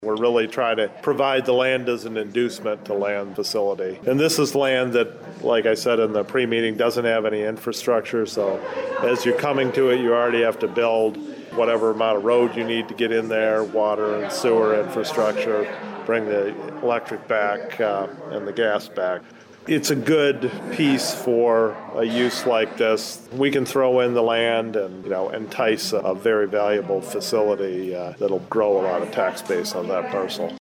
Elliott said the sale price for the Witt Farm would be “nominal”…